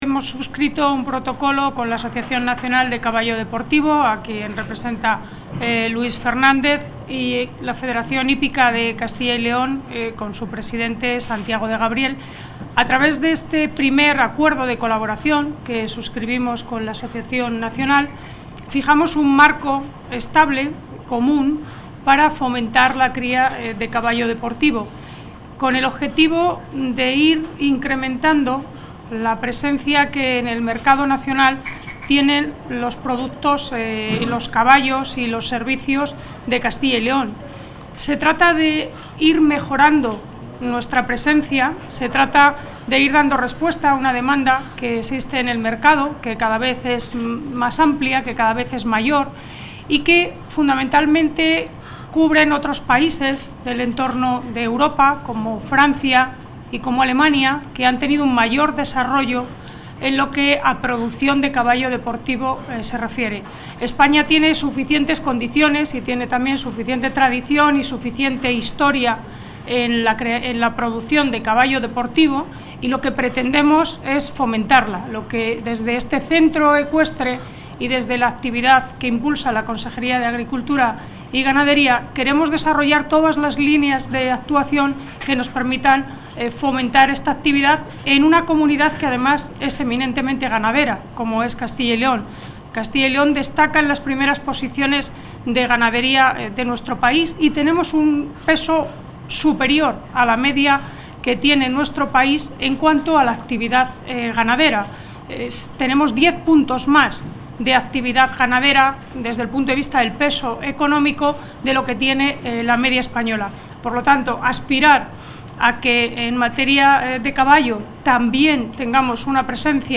Audio consejera.
La consejera de Agricultura y Ganadería, Silvia Clemente, ha presentado esta mañana las actividades que organiza el Centro Ecuestre de Castilla y León a lo largo de este año. Este Centro acogerá 46 campeonatos y concursos nacionales e internacionales, en los que participarán 3.538 jinetes y cuyo impacto económico para Segovia alcanzará los 4,2 millones de euros.